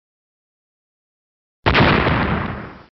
bomb.mp3